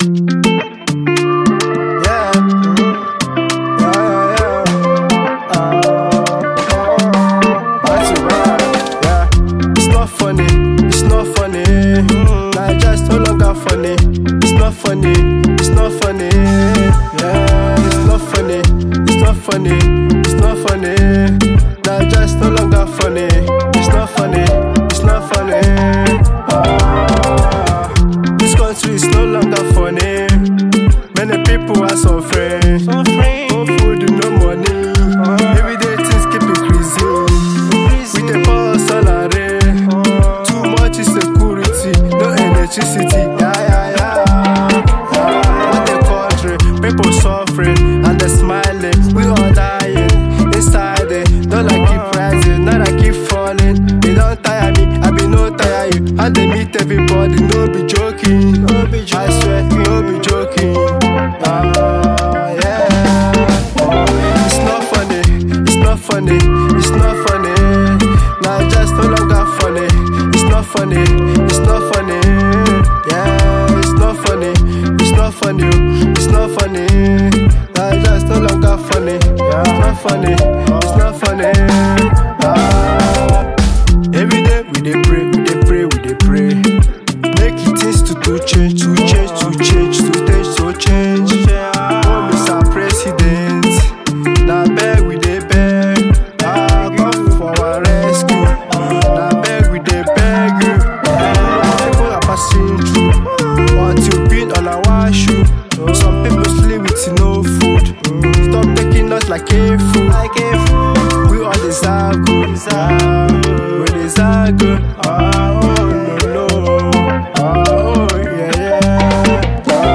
passionate delivery